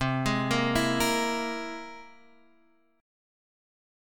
C+7 chord